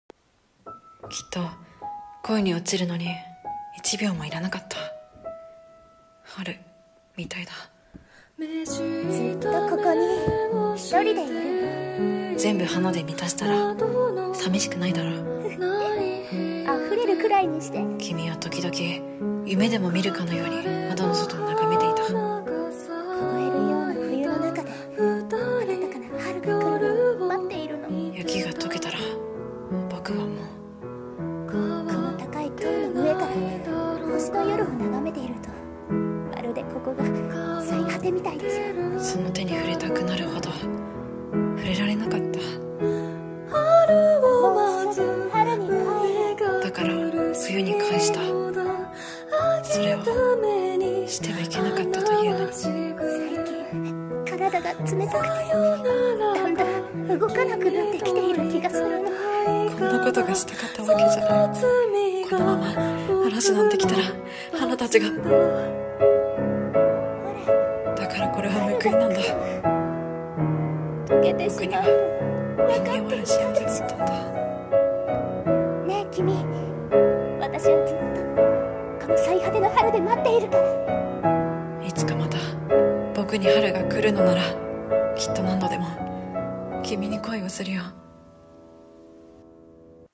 【予告風声劇】 最果ての春をきみと。